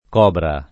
[ k 0 bra ]